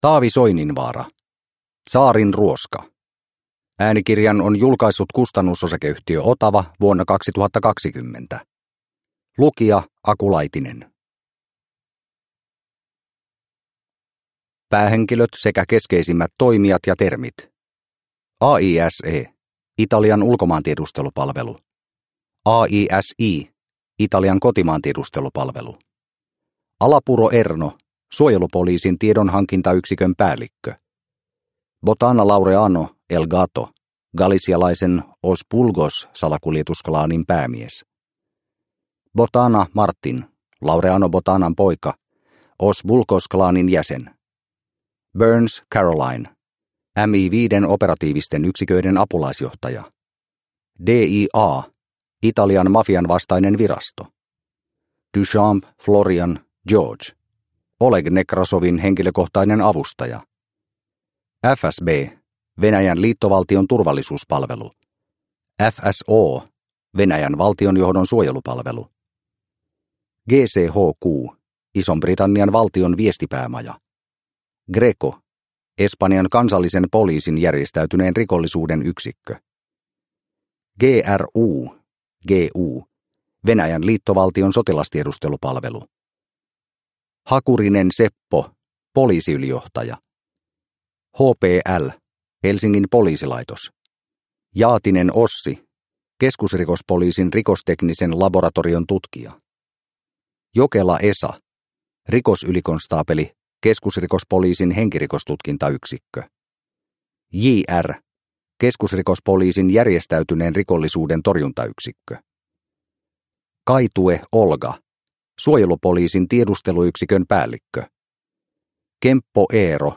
Tsaarin ruoska – Ljudbok – Laddas ner